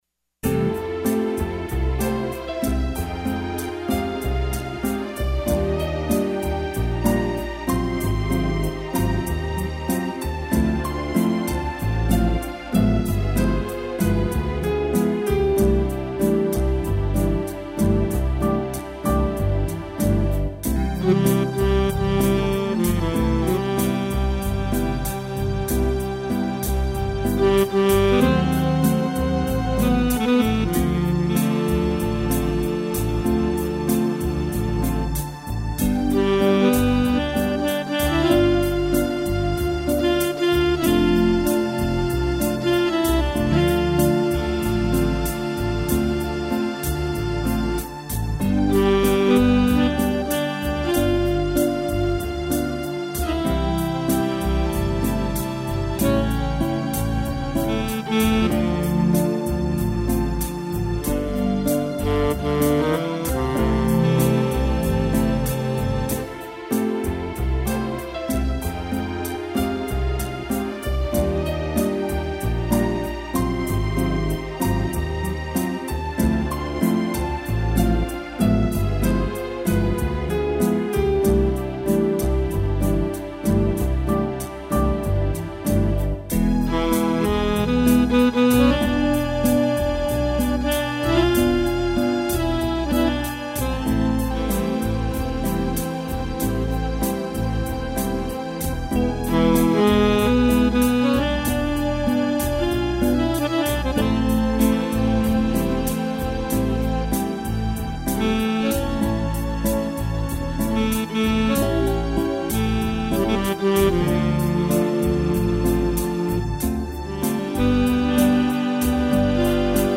bossa